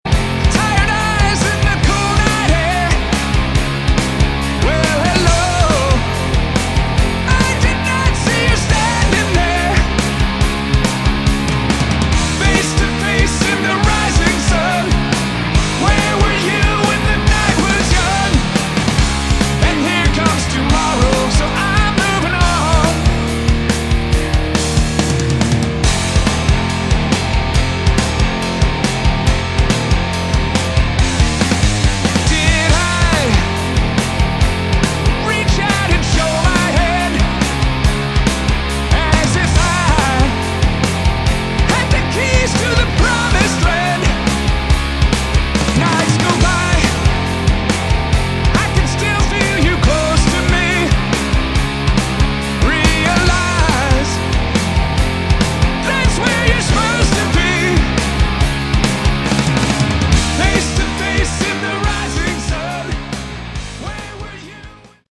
Category: Hard Rock
Vocals, All instruments